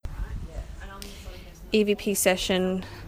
We also believe we may have captured another voice, in the upstairs hallway, which we are unable to determine what is said.